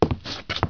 woodshuffle1.wav